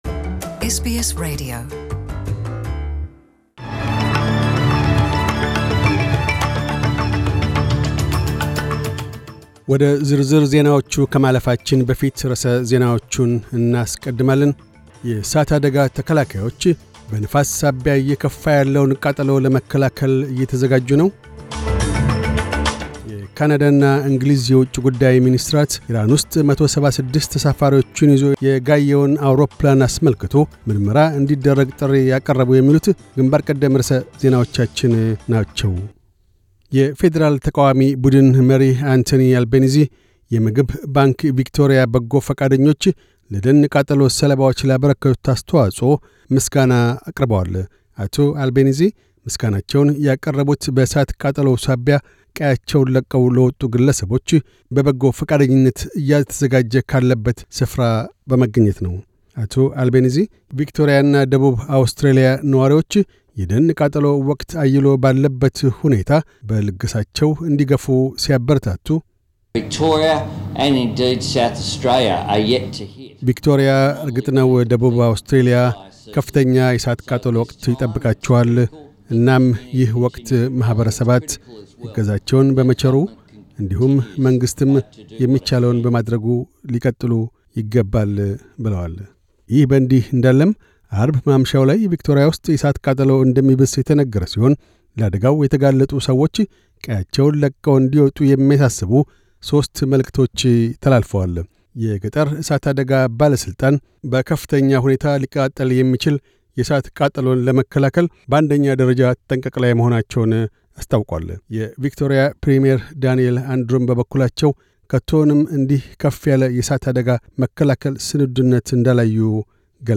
News Bulletin 1012